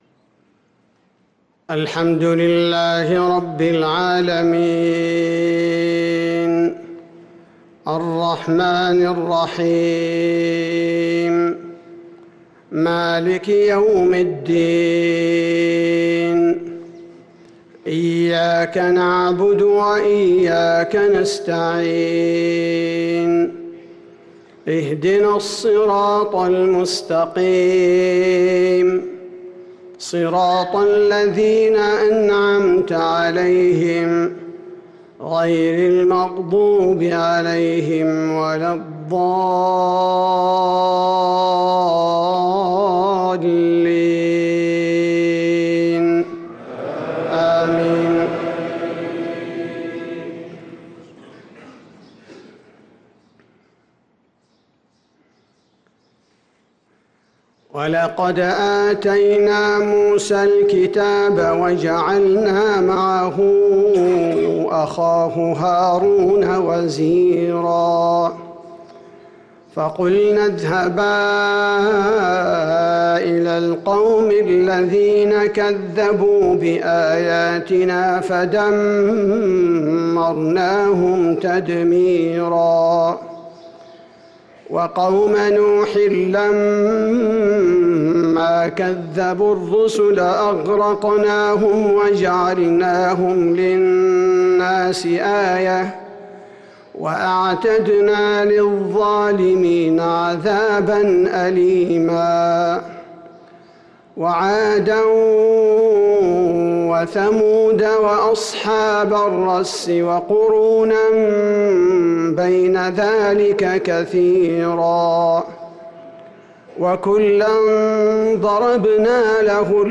صلاة المغرب للقارئ عبدالباري الثبيتي 20 رجب 1445 هـ